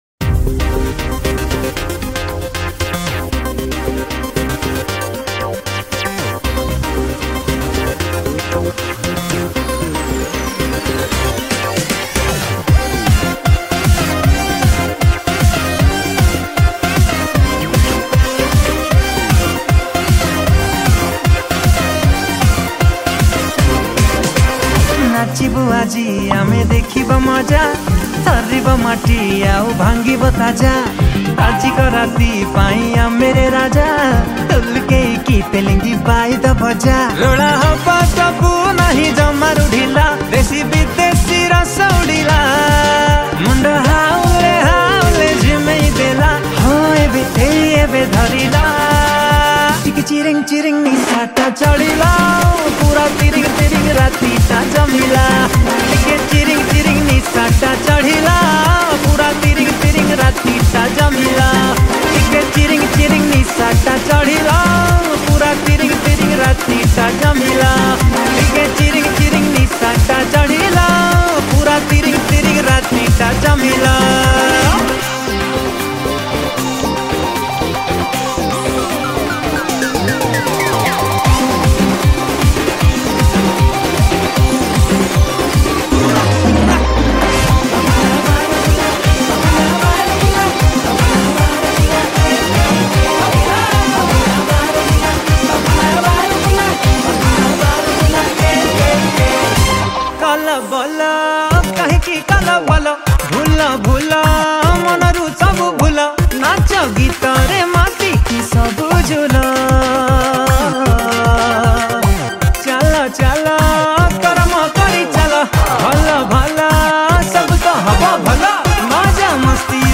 Sambalpuri Dhol
Kanjira
Timbale, Octapad and Additional Percussions
Male Chorus
Female Chorus